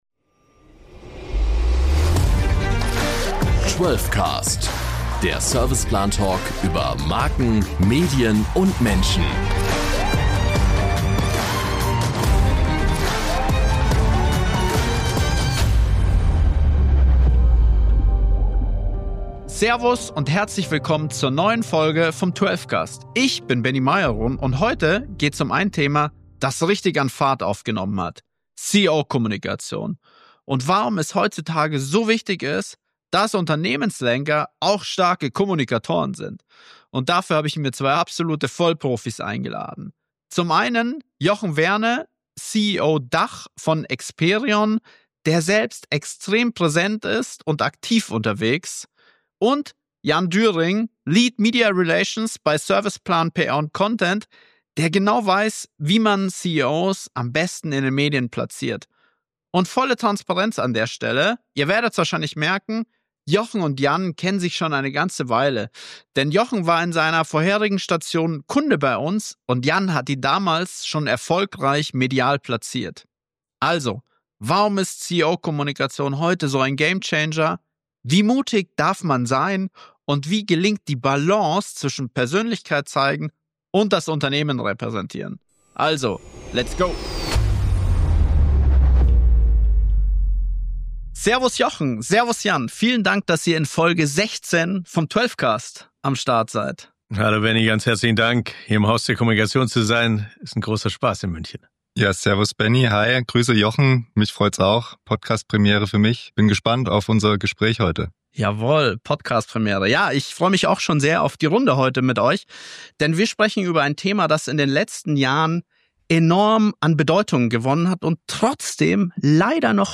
Eine Expertin oder einen Experten aus der Serviceplan Group und eine Kundin oder einen Kunden des Unternehmens.